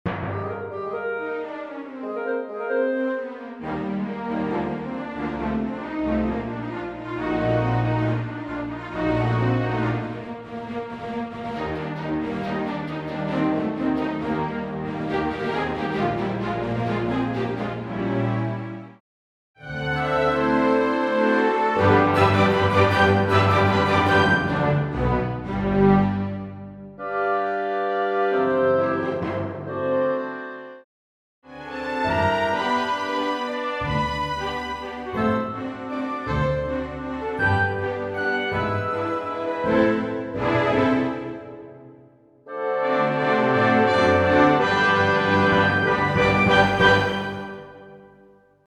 Full Orch accompaniment